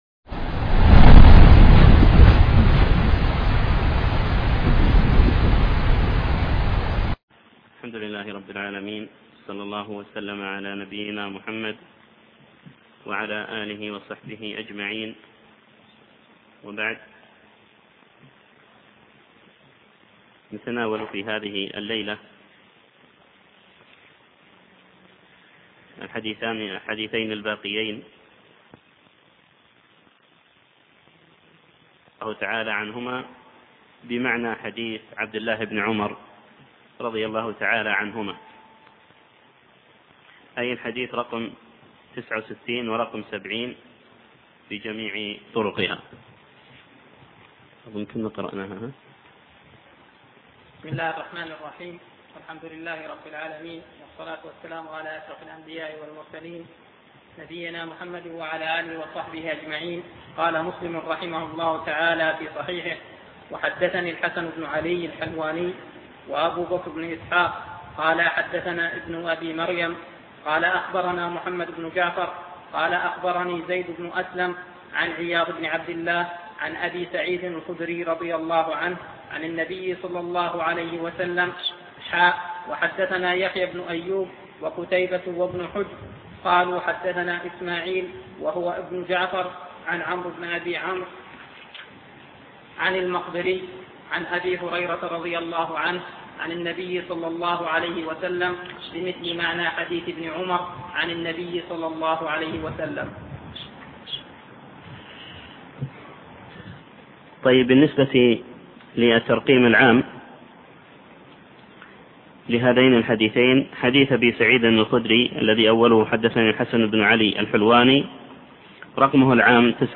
المحاضرة الثانية (حكم تارك الصلاة)